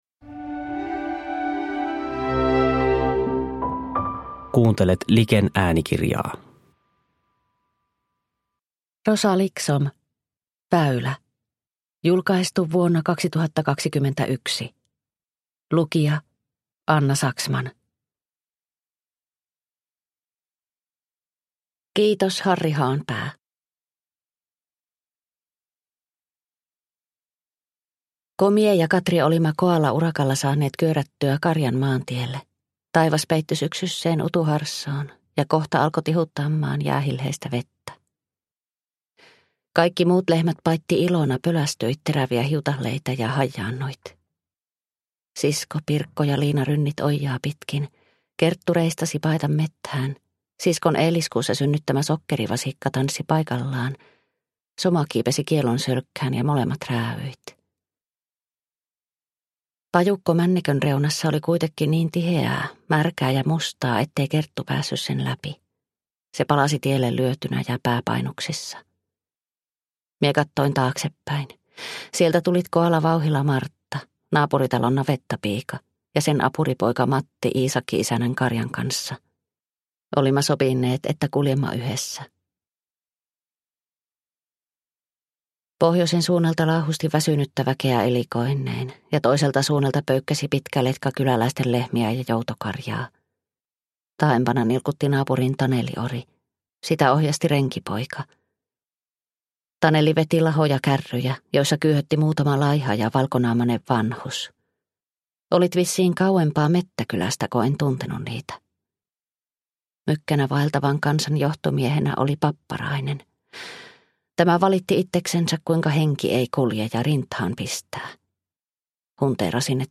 Väylä – Ljudbok – Laddas ner